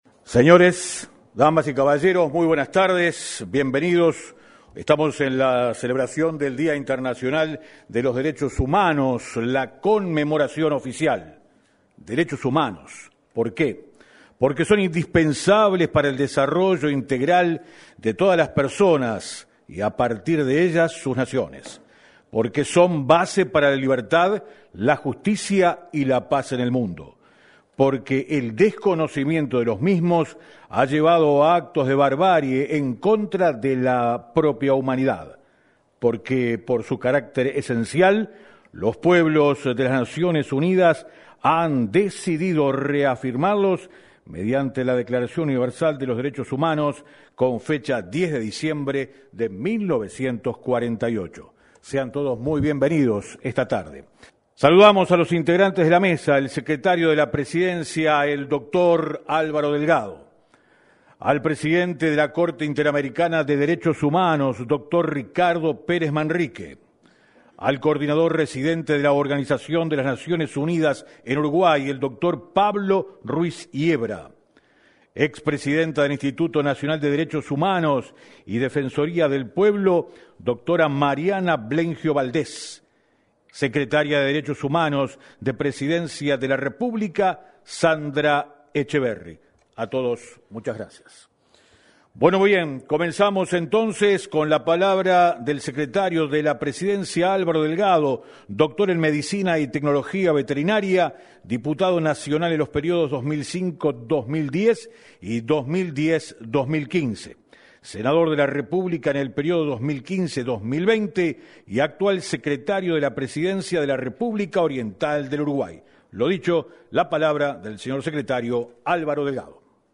En el evento, participaron el secretario de Presidencia de la República, Álvaro Delgado; el presidente de la Corte Interamericana de los Derechos Humanos, Ricardo Pérez Manrique; el coordinador residente de la ONU en Uruguay, Pablo Ruis Hiebra; la expresidenta del Instituto Nacional de Derechos Humanos y Defensoría del Pueblo Mariana Blengio Valdés y la secretaria de Derechos Humanos de Presidencia de la República, Sandra Etcheverry.